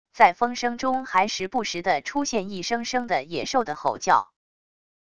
在风声中还时不时的出现一声声的野兽的吼叫wav音频